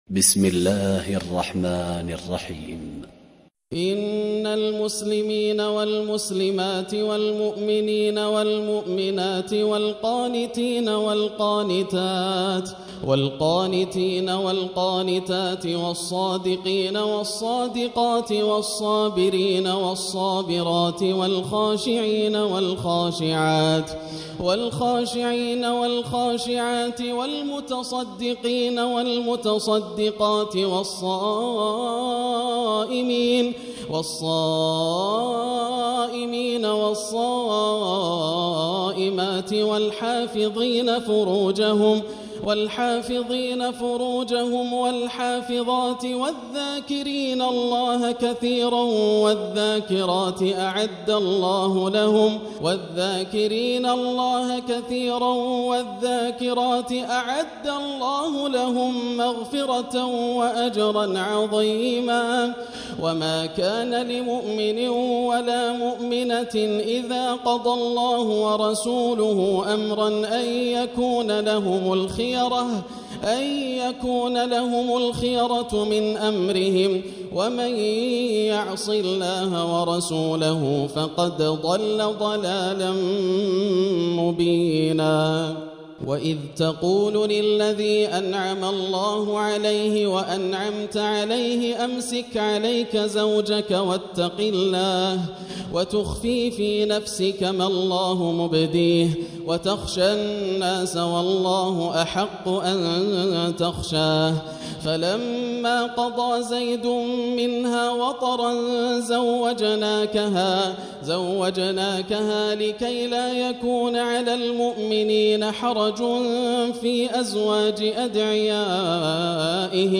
الليلة الحادي والعشرون - ما تيسر من سور الأحزاب (35-73) وسبأ (1-23) > الليالي الكاملة > رمضان 1440هـ > التراويح - تلاوات ياسر الدوسري